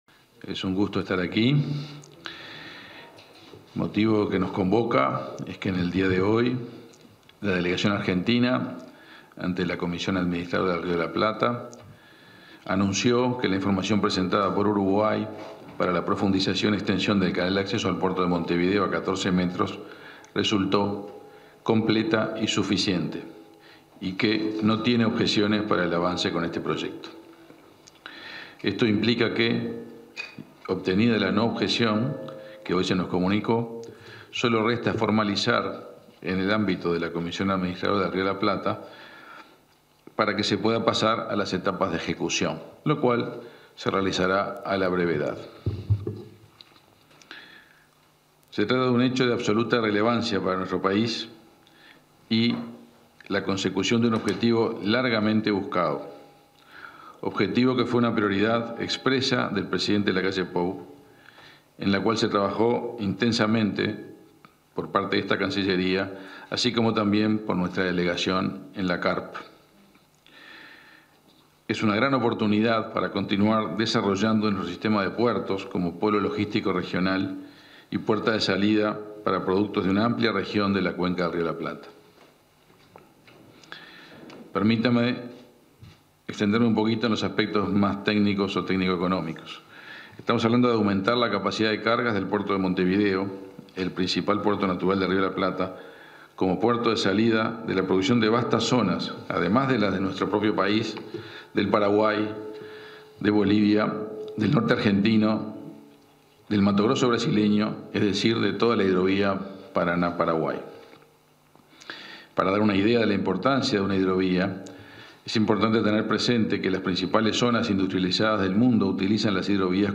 Conferencia del ministro de Relaciones Exteriores, Omar Paganini